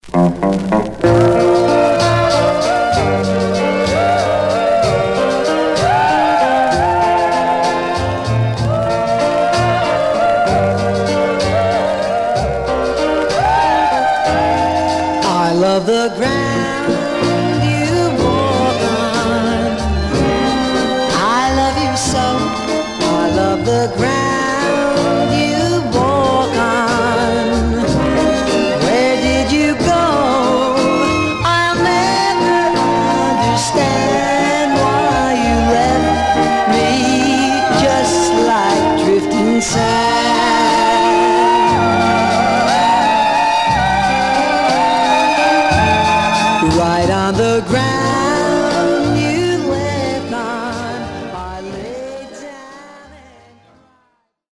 Surface noise/wear Stereo/mono Mono
Teen